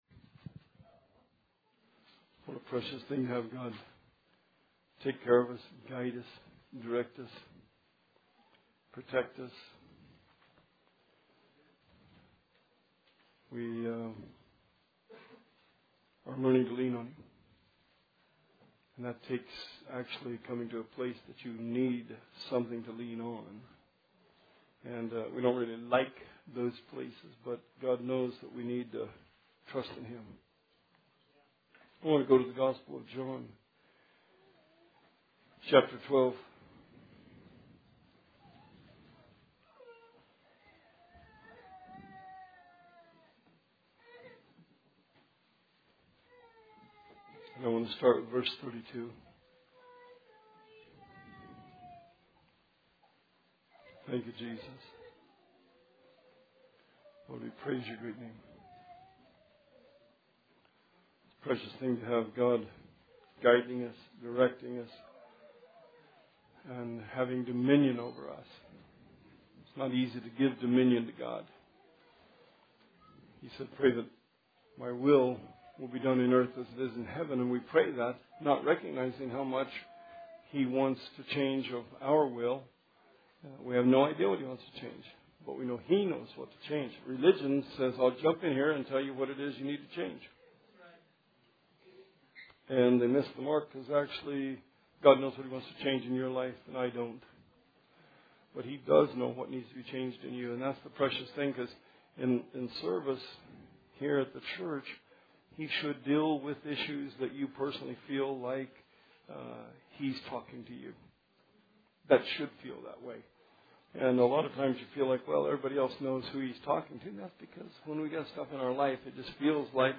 Series Sermon